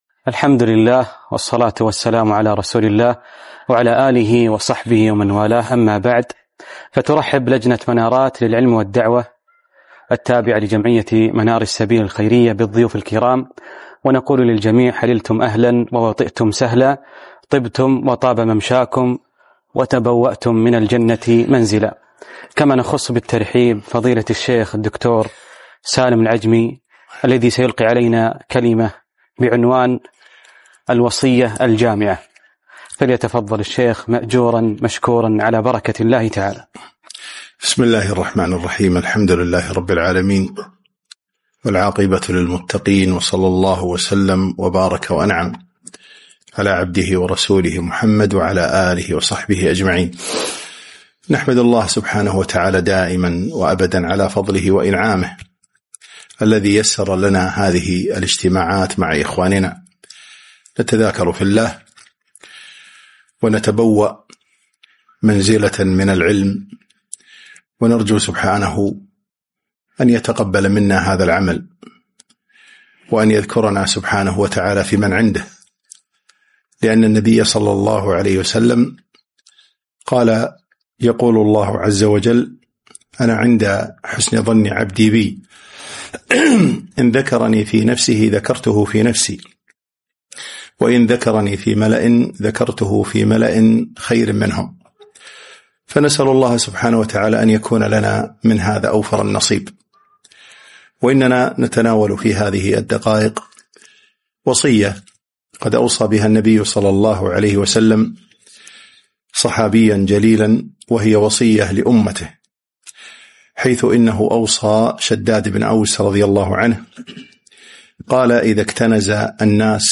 محاضرة - الوصية الجامعة